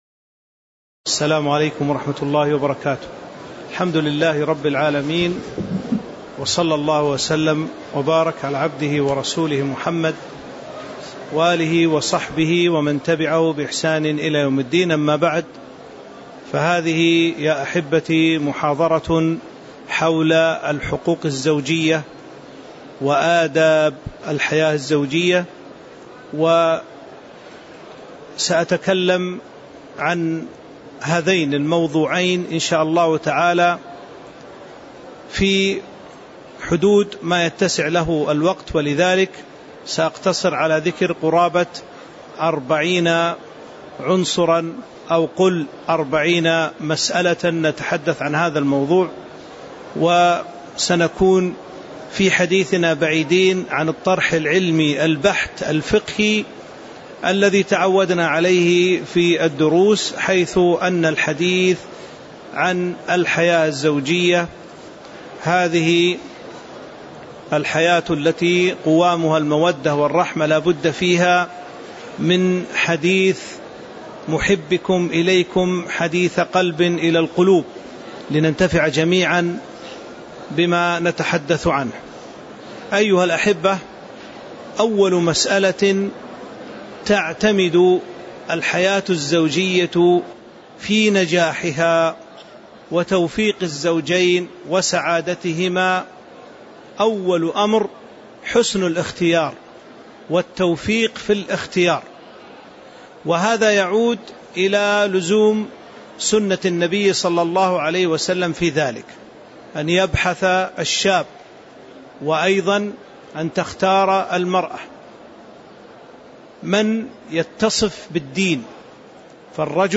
تاريخ النشر ١١ رجب ١٤٤٤ هـ المكان: المسجد النبوي الشيخ